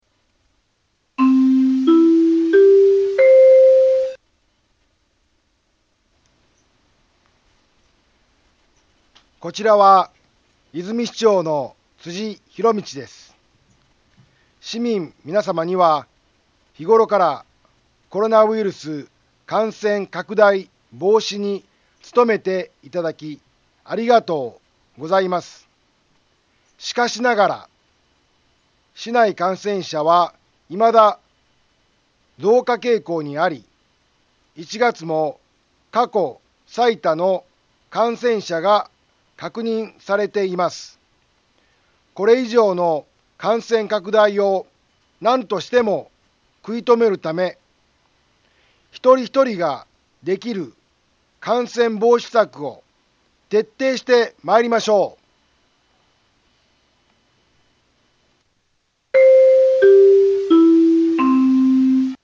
災害情報
Back Home 災害情報 音声放送 再生 災害情報 カテゴリ：通常放送 住所：大阪府和泉市府中町２丁目７−５ インフォメーション：こちらは、和泉市長の辻ひろみちです。